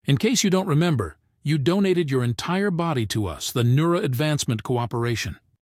anouncer_intro_03.ogg